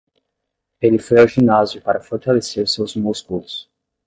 Pronunciado como (IPA)
/ʒiˈna.zi.u/